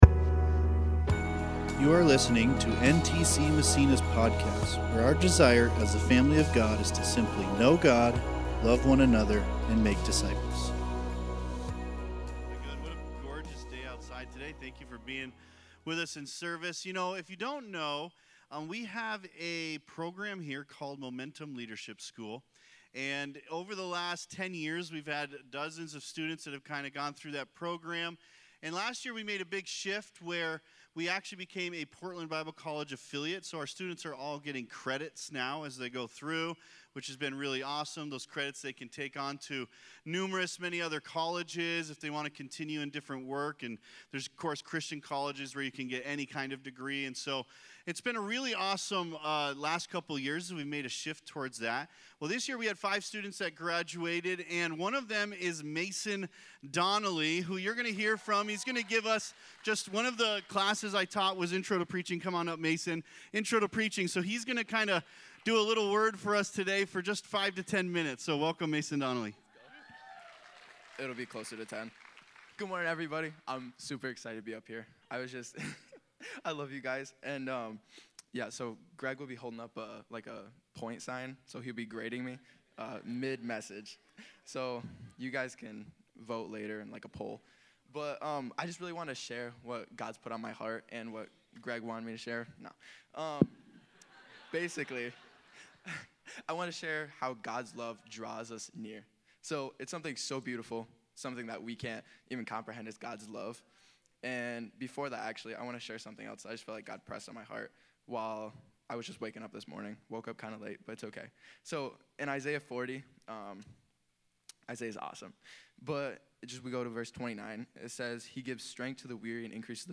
2024 Holy Spirit W.1- Pentecost Preacher